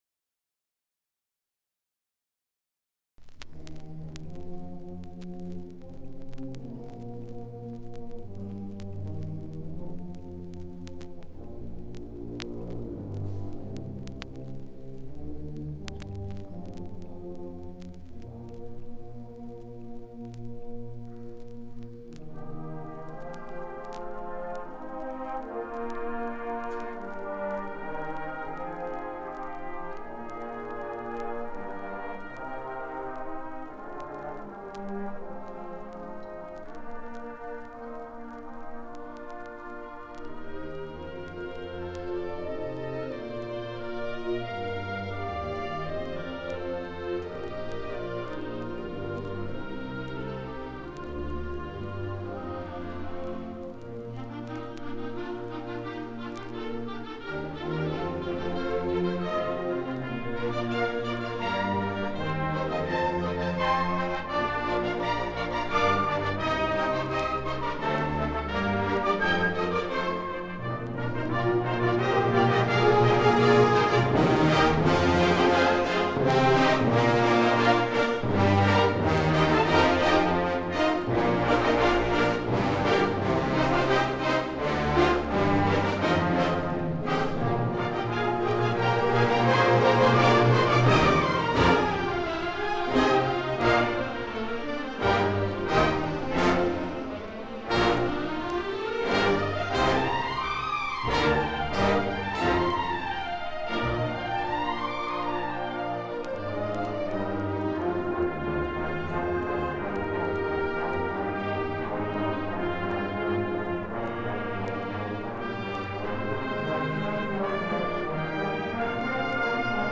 1983 Music in May band and orchestra performance recording · Digital Exhibits · heritage
9272ed69cbdd5ddfdb73af968aca8faf2c189dbb.mp3 Title 1983 Music in May band and orchestra performance recording Description An audio recording of the 1983 Music in May band and orchestra performance at Pacific University.
It brings outstanding high school music students together on the university campus for several days of lessons and events, culminating in the final concert that this recording preserves.